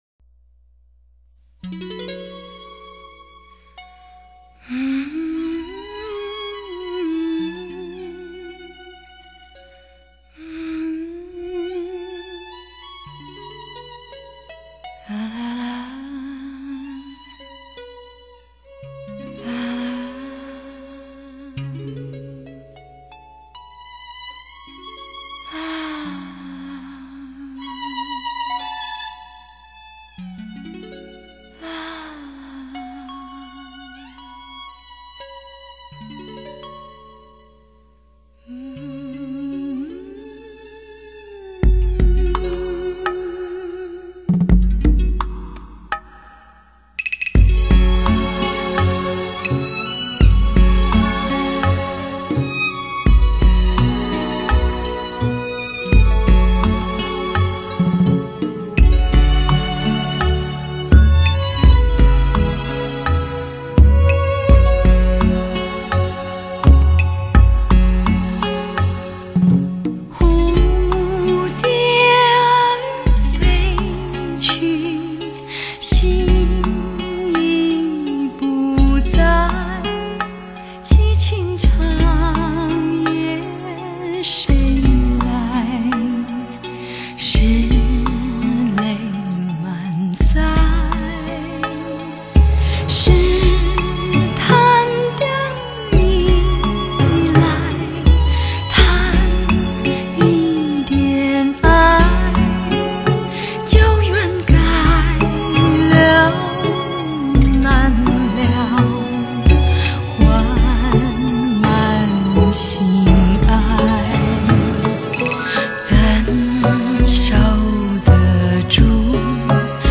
巅峰诱惑 听觉享受 专为你的爱车量身打造 与你的音响一起体验风驰电掣的激动